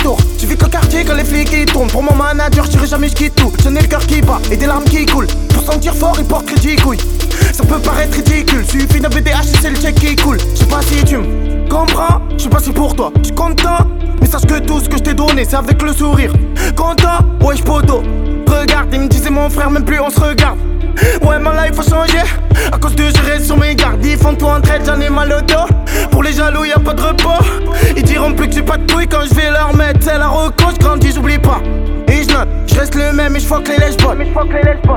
Hip-Hop Rap Rap French Pop
Жанр: Хип-Хоп / Рэп / Поп музыка